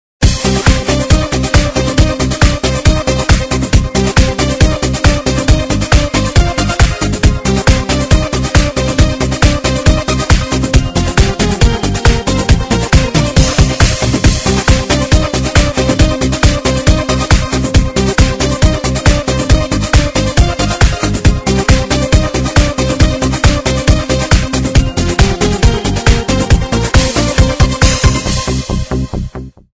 رینگتون پرانرژی و بیکلام